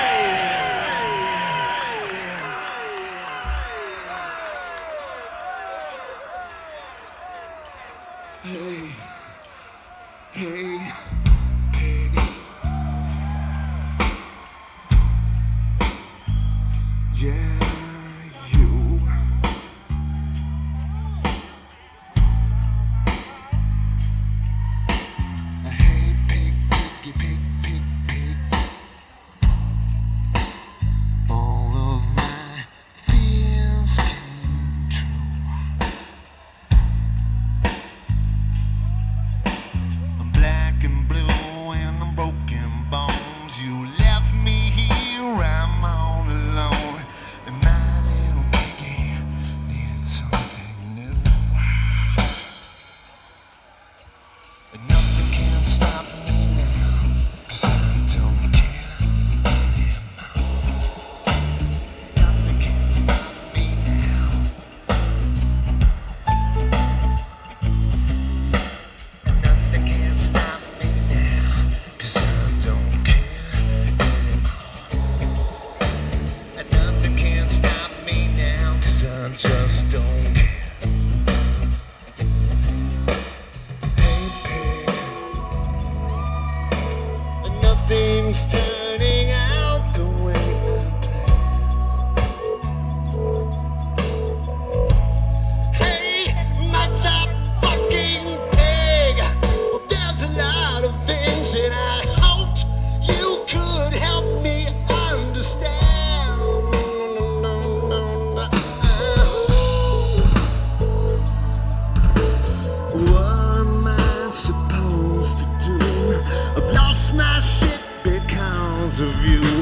Drums
Guitar
Vocals/Guitar/Keyboards